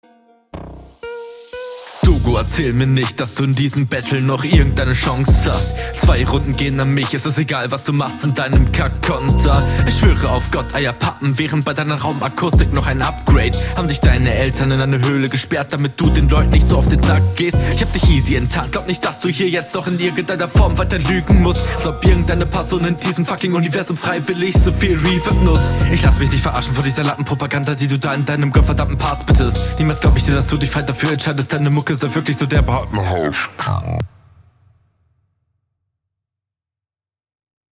Inhaltlich leider schwach aber flowlich und soundbild stark
Flowlich am Anfang besser als am Ende.